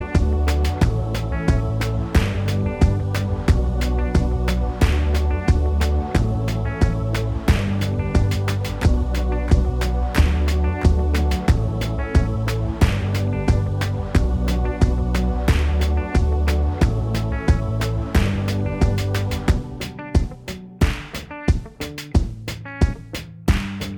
Minus All Guitars Pop (2010s) 3:19 Buy £1.50